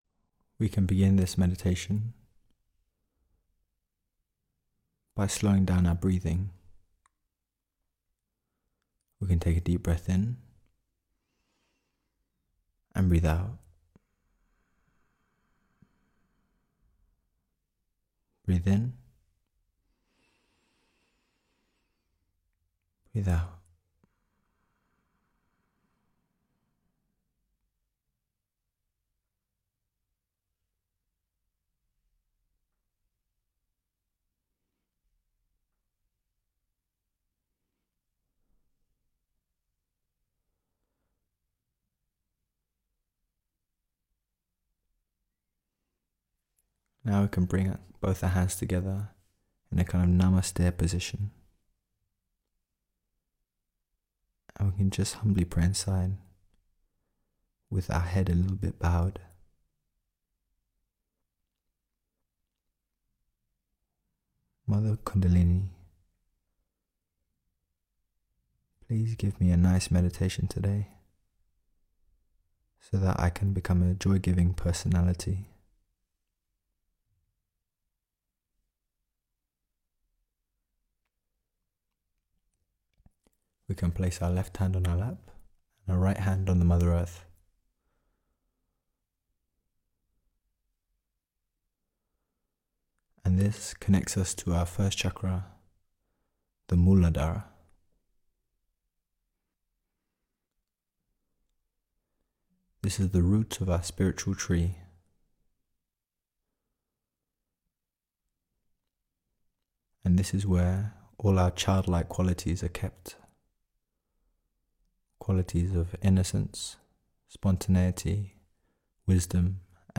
MEDITATION Shri Mataji Nirmala Devi founder 0:00 Delving Deeper All pervading power